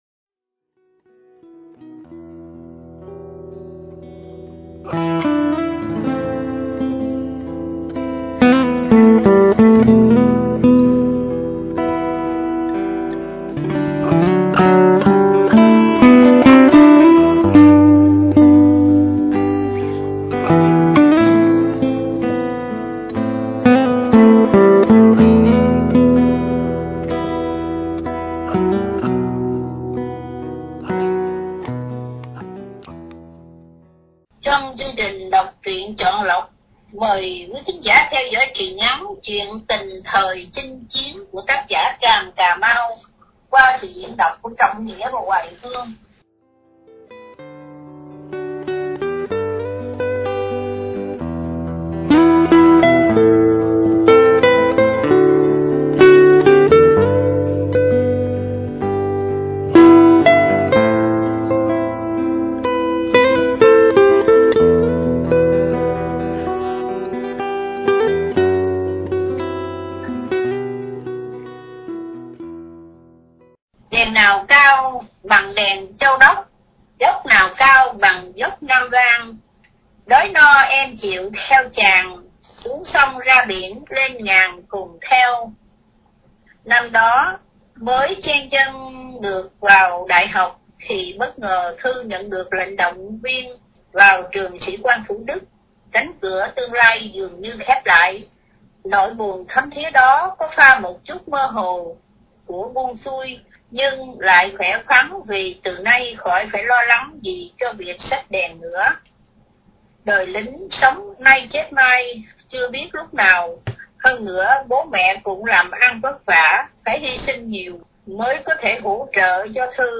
Đọc Truyện Chọn Lọc